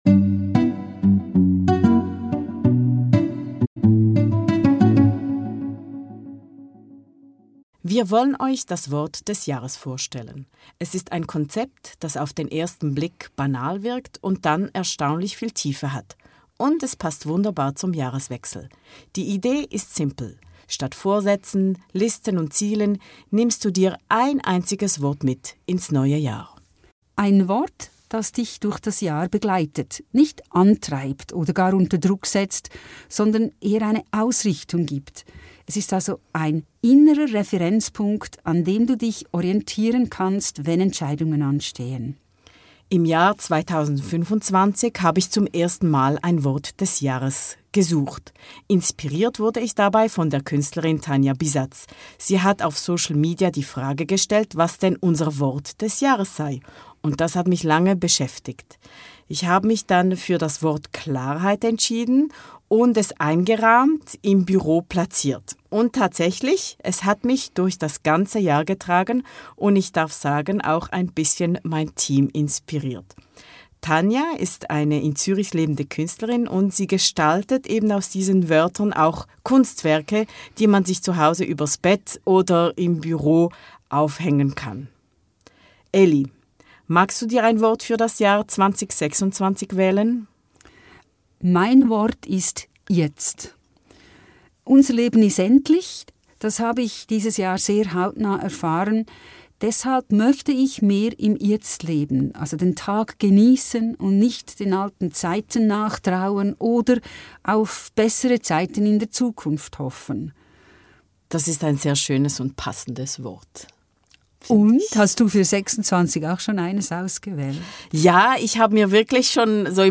FocusFuture - Zwei Generationen sprechen über die Zukunft.